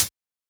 Closed Hats
edm-hihat-03.wav